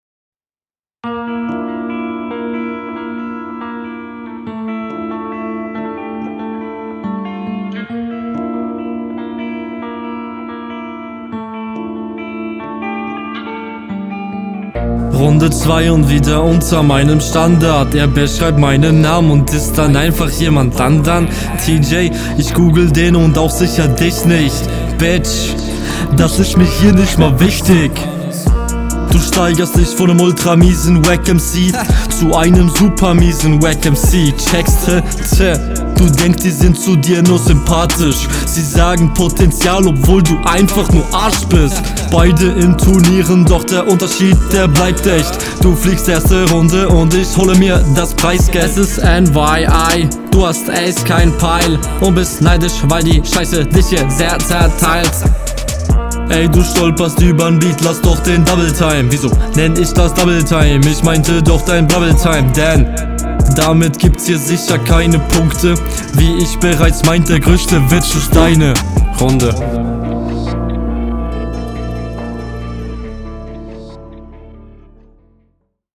Professioneller. kommst besser auf den Beat als dein Gegner. das gesamte Soundbild ist gut auch …